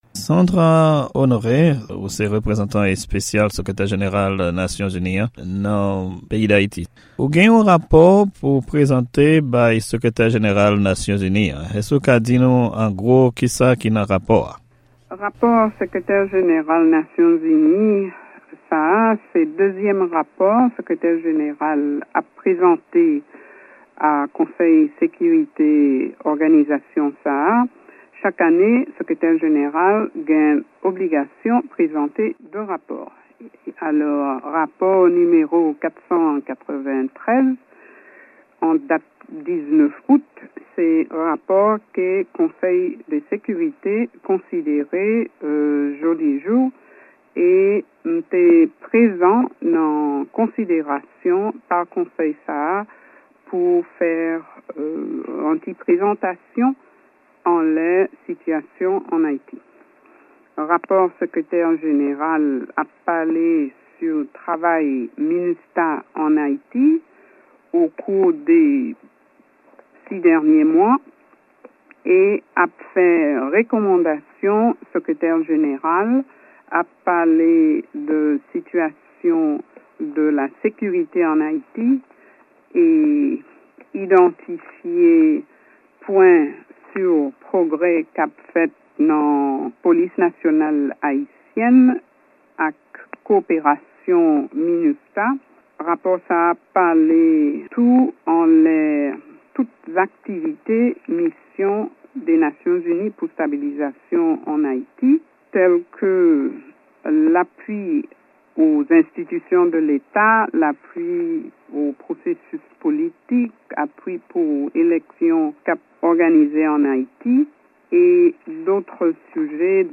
Entèvyou ak lidè sivil Minustah Sandra Honoré